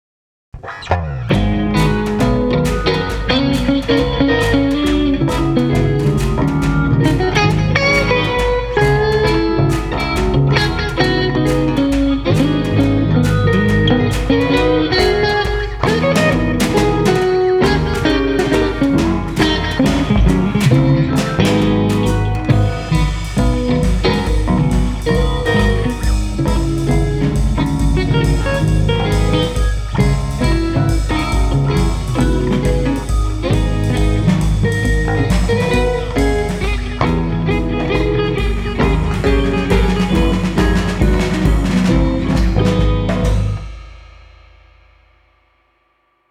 48kHz/24bit/ステレオ/wav
ブルース
ギター
クール
怪しい